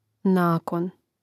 nákon nakon